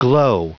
Prononciation du mot : glow